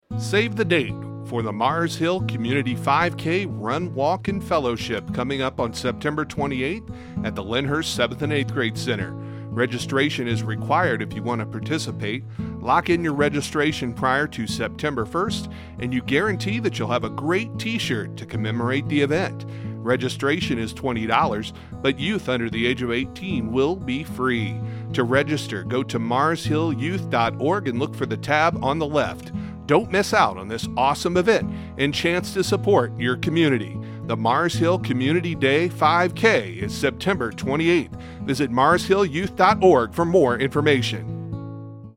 Mars Hill 5K Community Day PSA